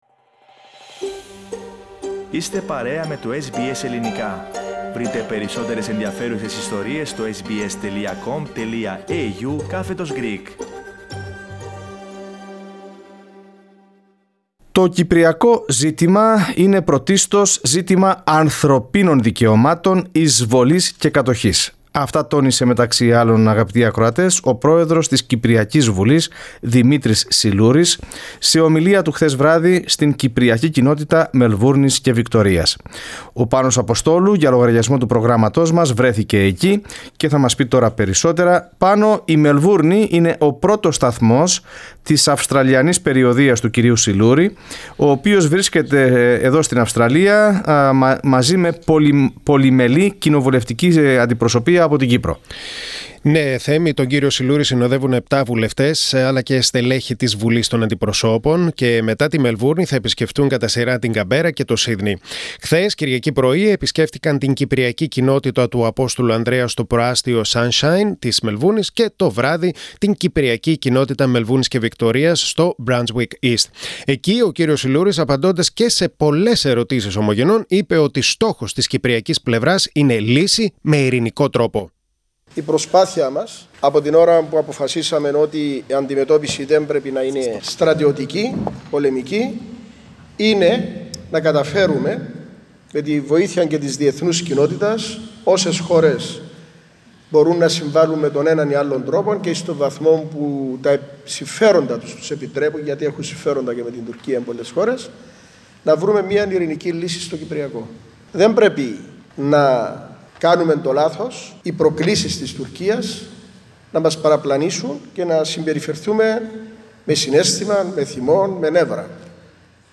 Ο πρόεδρος της Κυπριακής Βουλής, Δημήτρης Συλλούρης, βρίσκεται στην Αυστραλία για επίσημη επίσκεψη. Πρώτος του σταθμός η Μελβούρνη, όπου μίλησε σε ομογενείς στην Κυπριακή Κοινότητα Μελβούρνης.
At the Cyprus Community of Melbourne and Victoria.